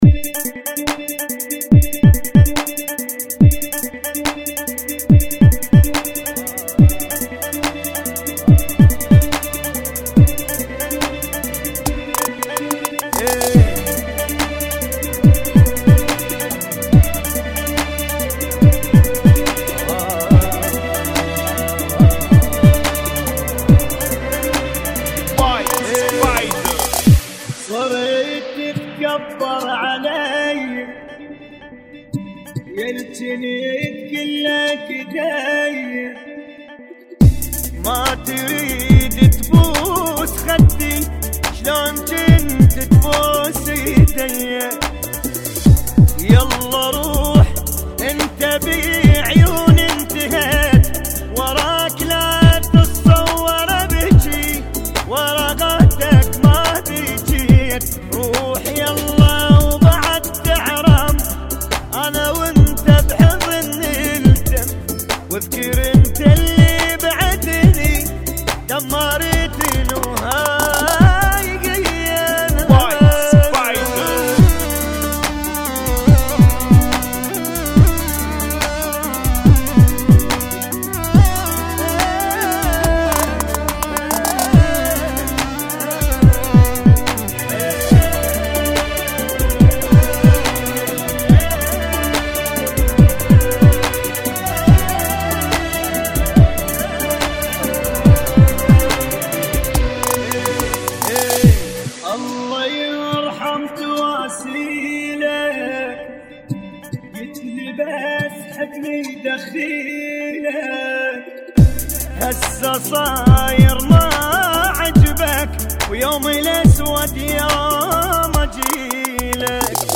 Funky [ 71 Bpm ]